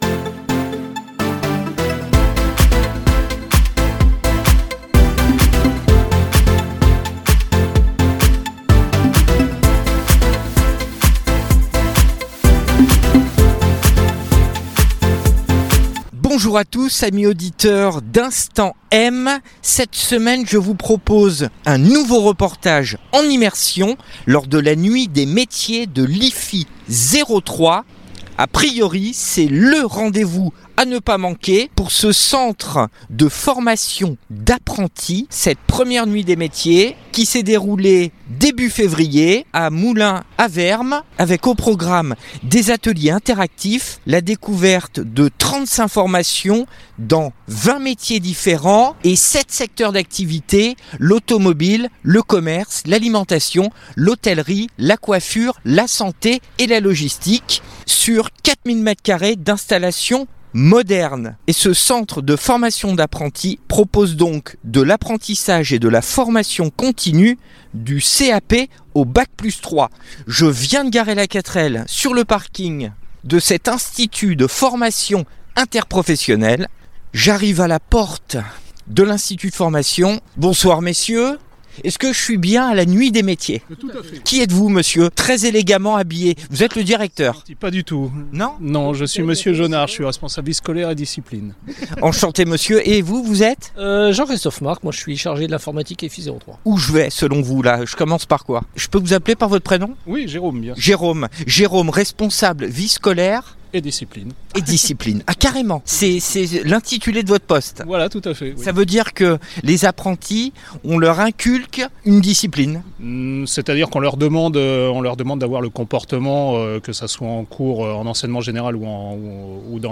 Rencontre avec différents acteurs : enseignants, apprentis